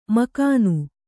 ♪ makānu